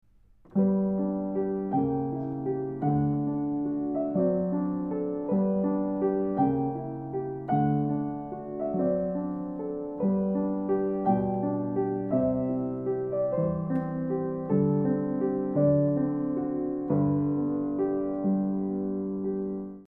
Pianola